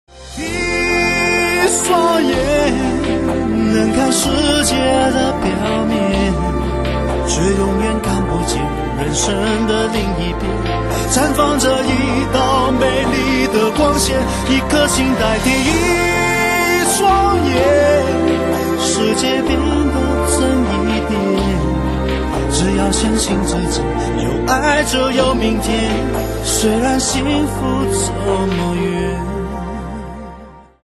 优雅的旋律+女声轻和